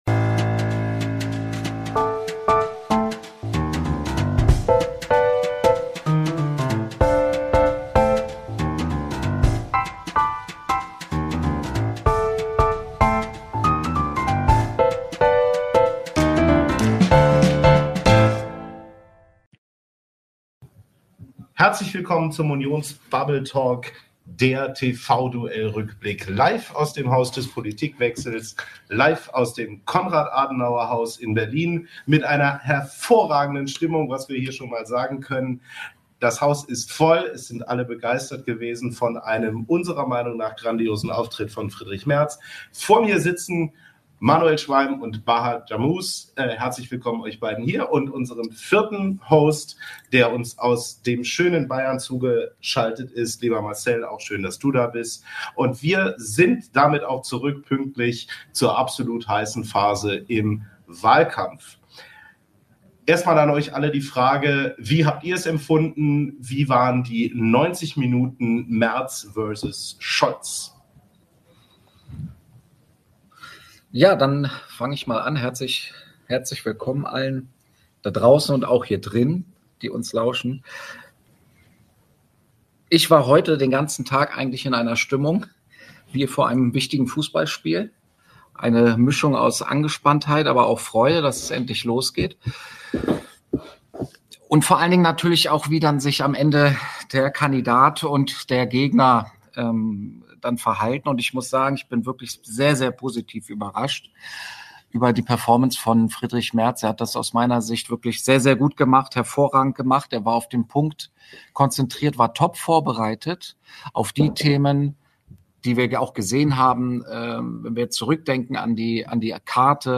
Nach dem TV-Duell von Olaf Scholz und Friedrich Merz blicken wir auf die Details und unsere Eindrücke - live aus dem Konrad-Adenauer-Haus in Berlin. Auch der Generalsekretär der CDU Deutschlands, Carsten Linnemann, und der Mitgliederbeauftragte Philipp Amthor waren zu Gast.